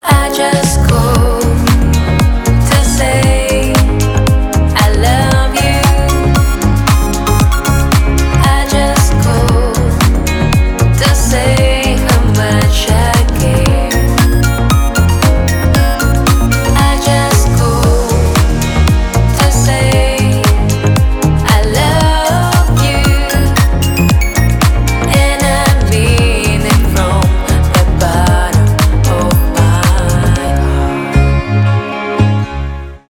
ремиксы , dance pop
retromix